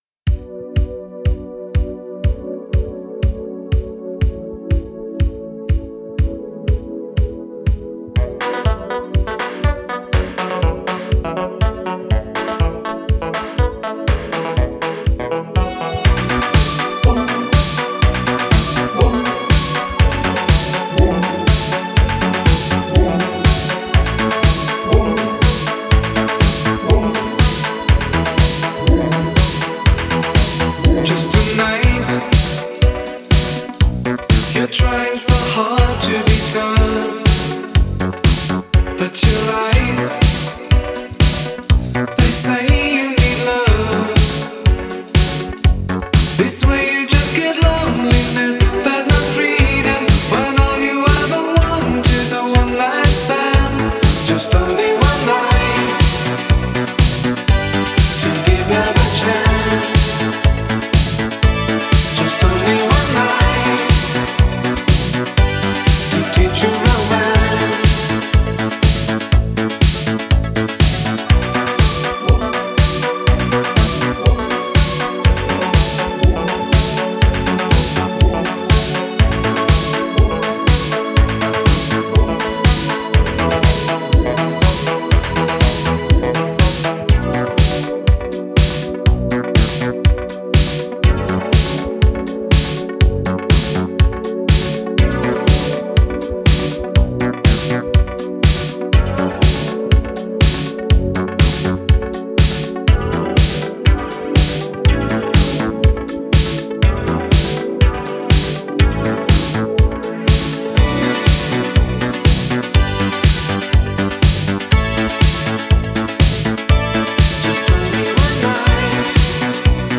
Жанр: Italo Disco, Synth Pop